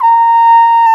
Index of /90_sSampleCDs/Roland LCDP12 Solo Brass/BRS_Cornet/BRS_Cornet 2